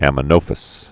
(ămə-nōfəs)